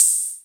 • Muted Open Hi Hat One Shot C# Key 11.wav
Royality free open hi hat sample tuned to the C# note. Loudest frequency: 7462Hz
muted-open-hi-hat-one-shot-c-sharp-key-11-WwN.wav